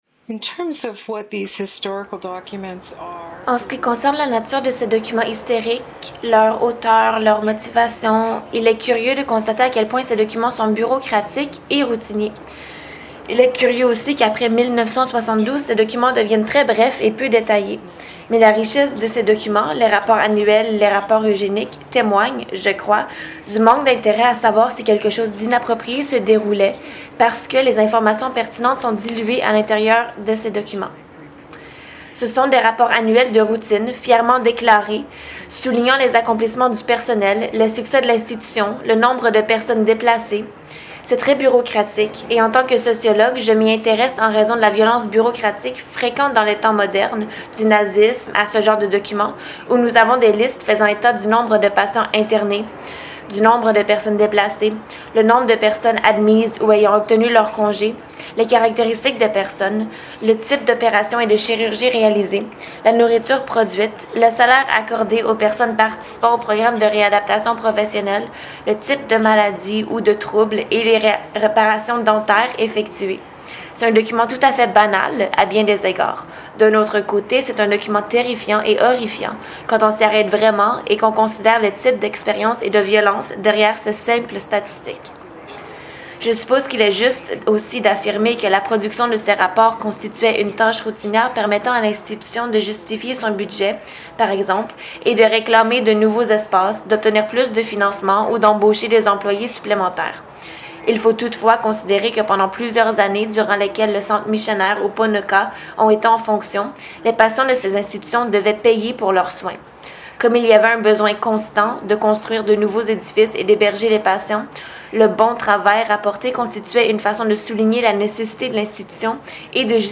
Cet ensemble de documents historiques et de commentaires audio permet aux étudiants de démêler les raisonnements derrière la Loi de la stérilisation sexuelle de 1928 en Alberta ainsi que ses amendements.